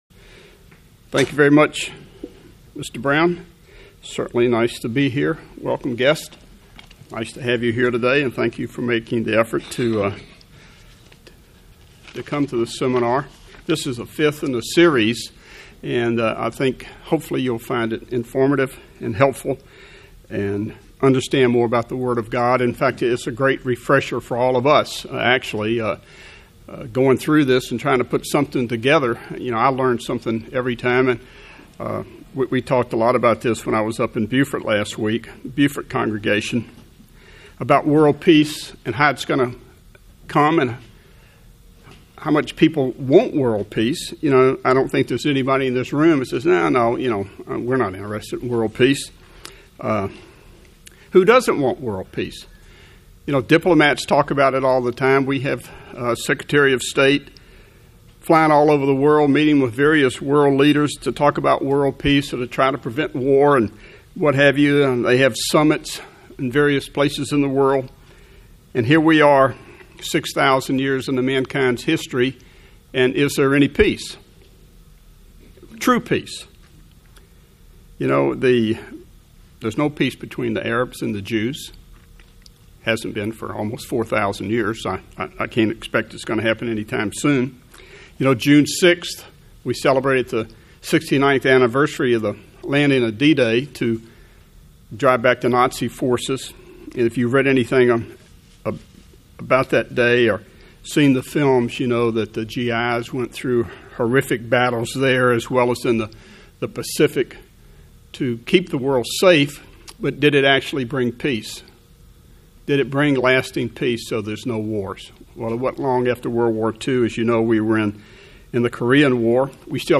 United Church of God congregation sponsored a Kingdom of God Bible Seminar for readers or the Good News Magazine, Beyond Today TV program viewers and others interested in understanding what Christ preached about the soon-coming Kingdom of God.
Given in Atlanta, GA
UCG Sermon Studying the bible?